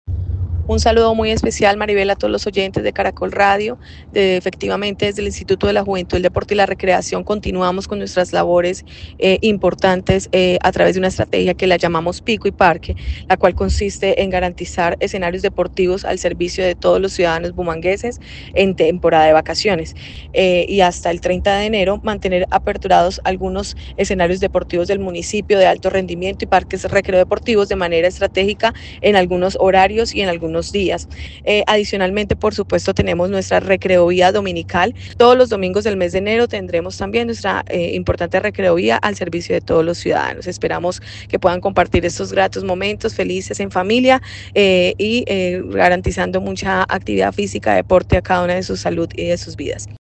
La recreovía dominical estará al servicio de todos los ciudadanos durante todo enero.